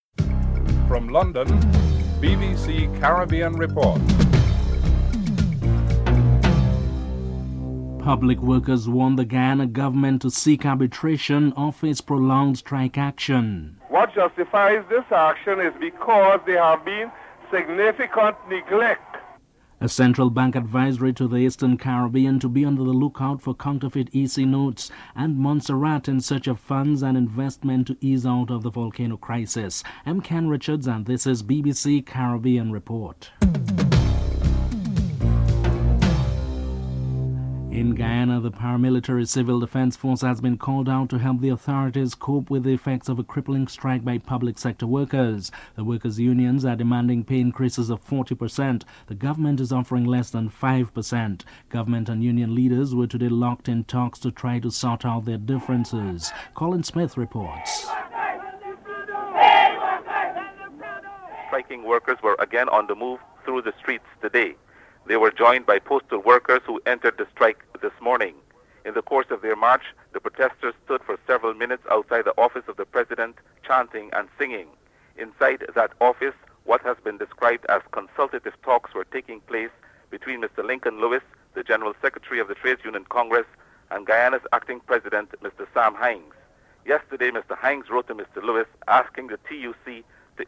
Peter Greste reports (05: 24 - 07: 18)
7. In the House of Commons, British Prime Minister Tony Blair restates his government intention to punish those responsible for the nail bomb attack aimed at London Afro Caribbean, Asian and Gay communities (12: 36 - 13: 19)